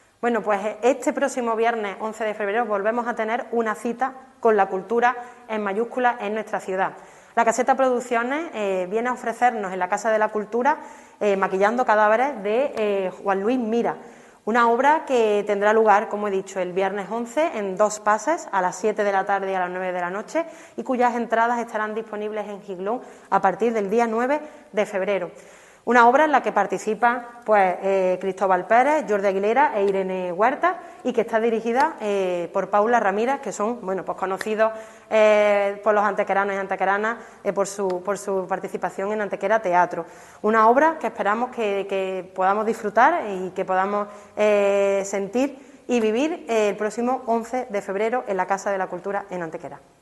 La teniente de alcalde delegada de Cultura y Tradiciones, Elena Melero, informa del desarrollo de una nueva actividad cultural promovida por el Ayuntamiento de Antequera que supone el regreso de la actividad teatral escénica a nuestra ciudad.
Cortes de voz